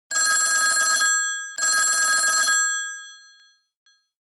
alarm1.mp3